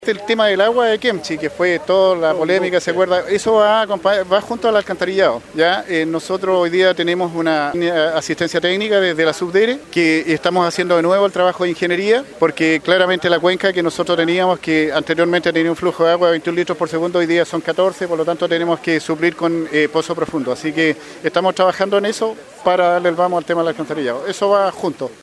El jefe comunal quemchino mencionó que como se está trabajando para que las obras del pozo profundo puedan comenzar lo antes posible.
CUÑA-2-GUSTAVO-LOBOS-.mp3